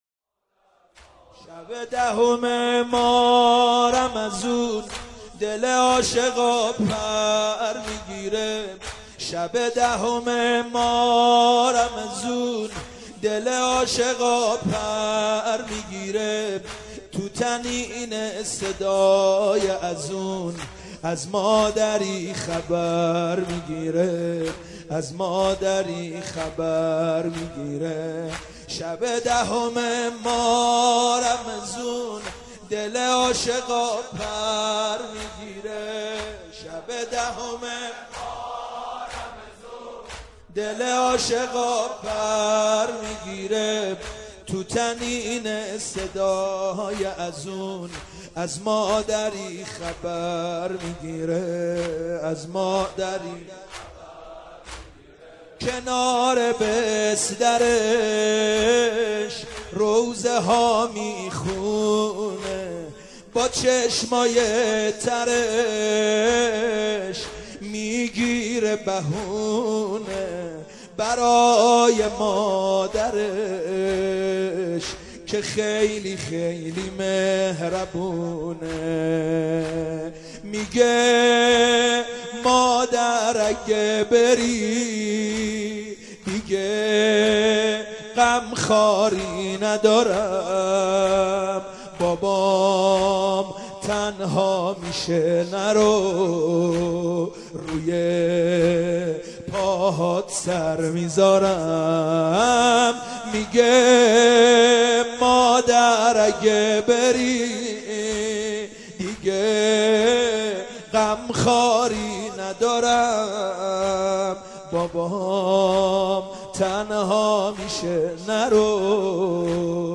دانلود مداحی وفات حضرت خدیجه (س) حاج سید مهدی میرداماد